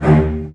CELLOS.FN2 S.wav